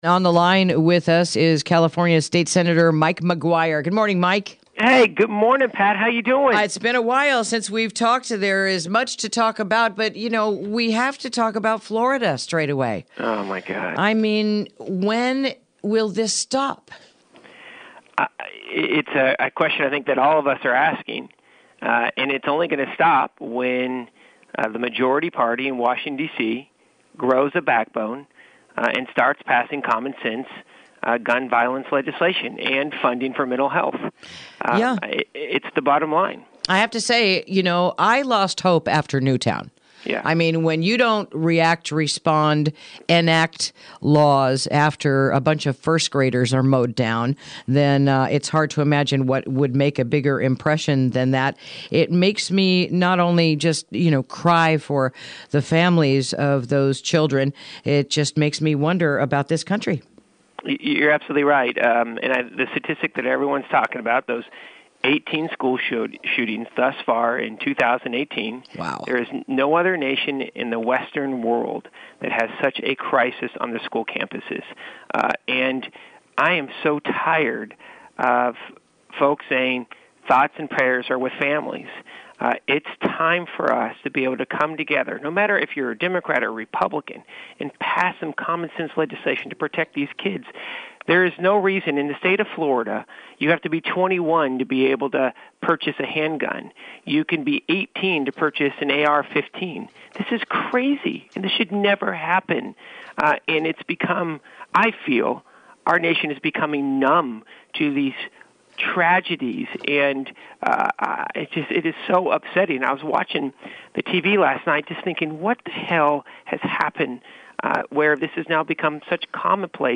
Interview: Fire Recovery Efforts and the RCU Fire Relief Fund